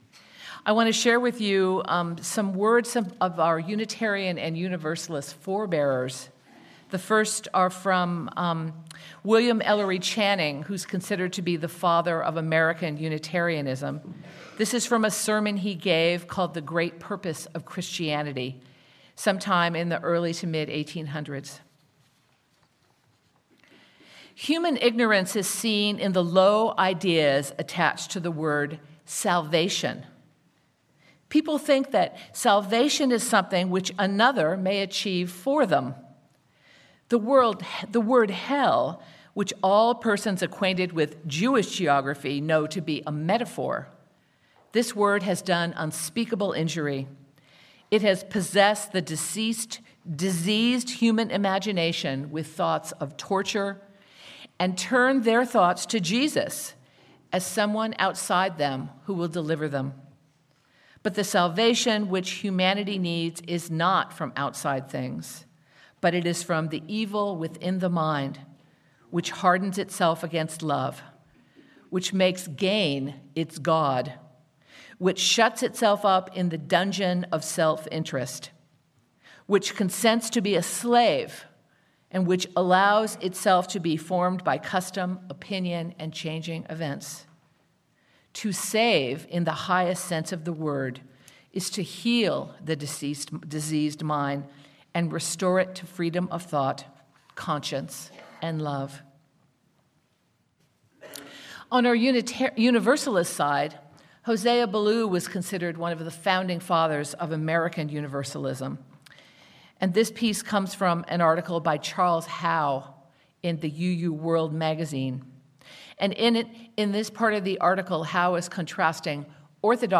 0:00 Reading part 1 1:40 Reading part 2 3:35 Sermon
Sermon-UU-Theology-Salvation.mp3